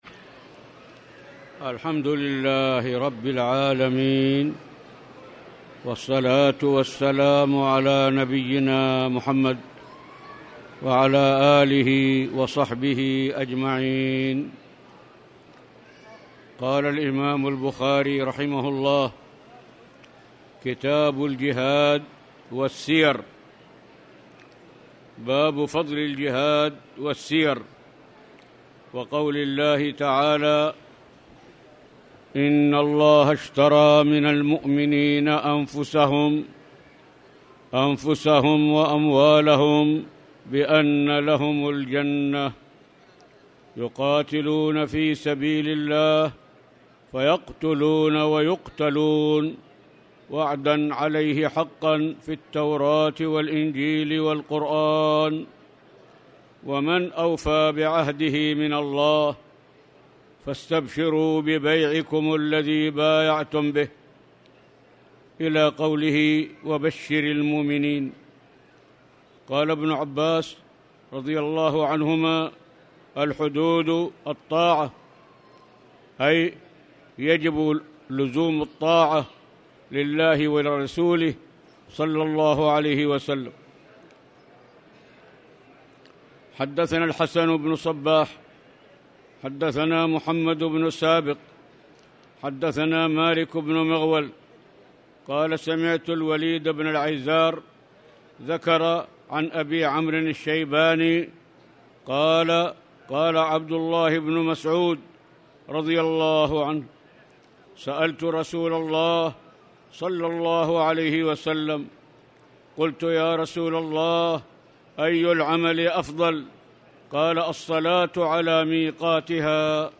تاريخ النشر ١١ شوال ١٤٣٨ هـ المكان: المسجد الحرام الشيخ